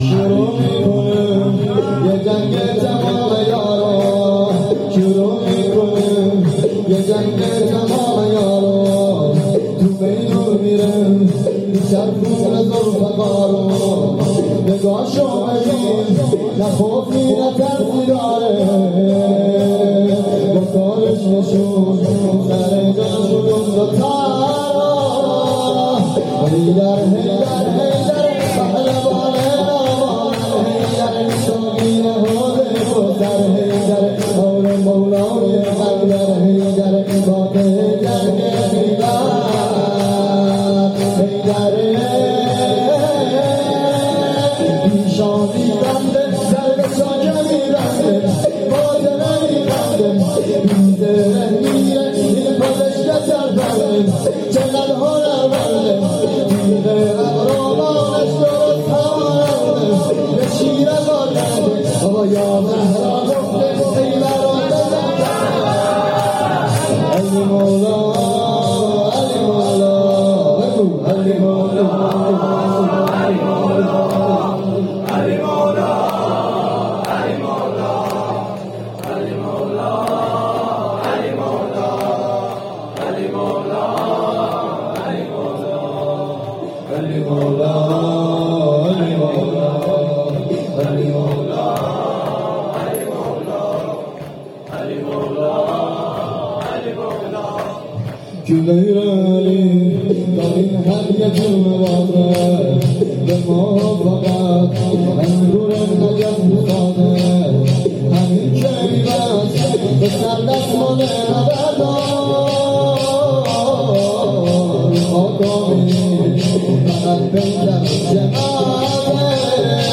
جلسه هفتگی/4آبان1400/ 19ربیع الاول1443